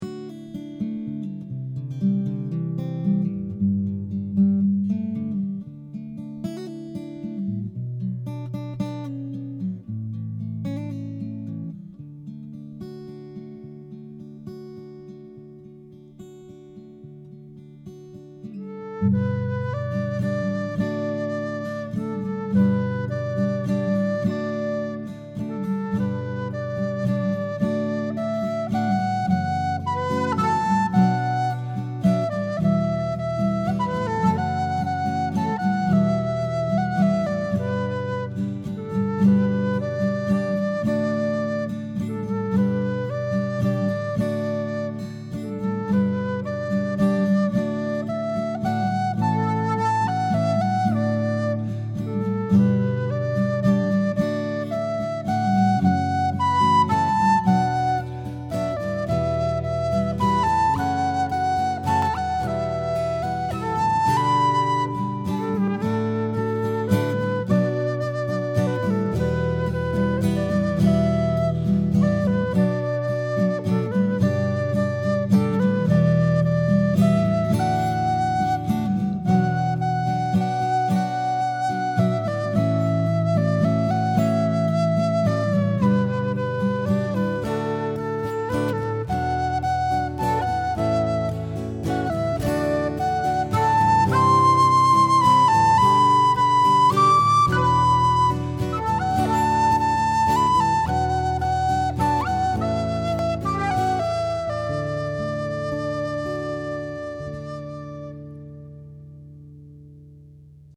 The Seans – Celtic – Scottish – Irish Duo
An example of a traditional Celtic tune – Give Me Your Hand for an aisle song
Give-Me-Your-Hand-Flute-Guitar-Duet-Final.mp3